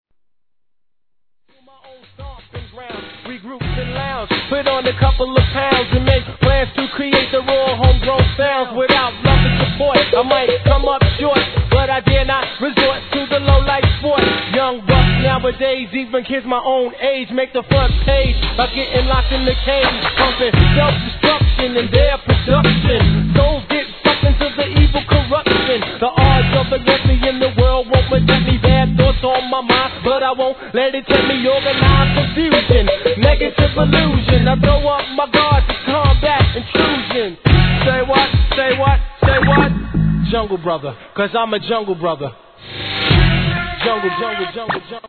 HIP HOP/R&B
ベースラインの効いたALBUM VER